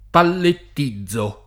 pallett&zzo] — anche palettizzare: palettizzo [palett&zzo] — la forma con -l- doppia, modellata sull’ingl. pallet; quella con -l- scempia, sull’equivalente it. paletta (di carico)